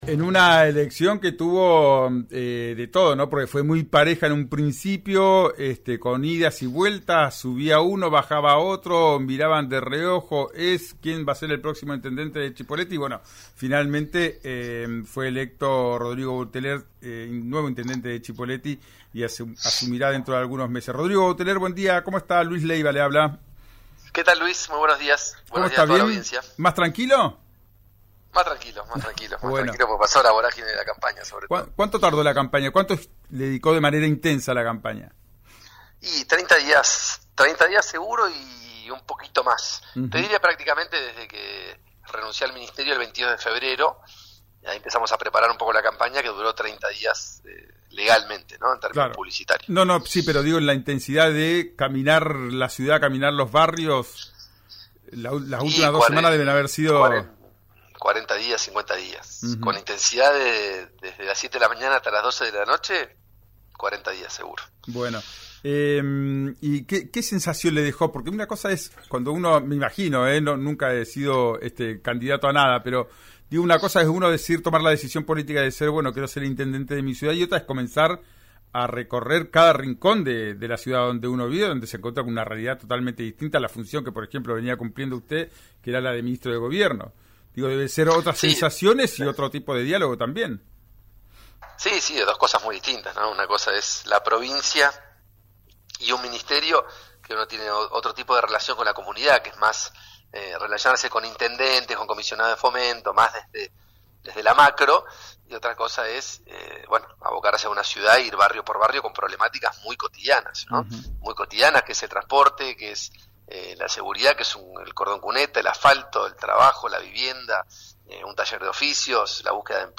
El intendente electo habló con Río Negro Radio para marcar su punto de vista sobre las formas de llevar adelante una gestión municipal. Aseguró que el diálogo con los vecinos es fundamental.